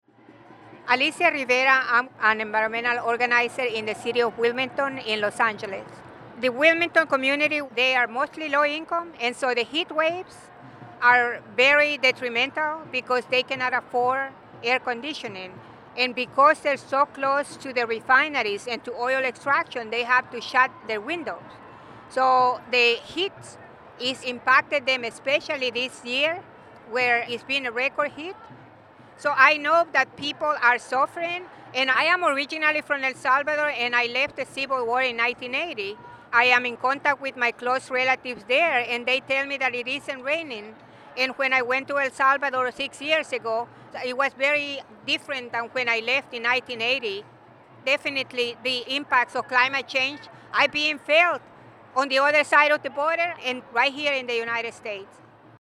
Here are some stories from the front lines of climate change that we gathered at the Global Climate Action Summit in San Francisco in mid-September (listen to each person talk by clicking the audio players below the images).